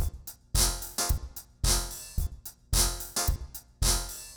RemixedDrums_110BPM_38.wav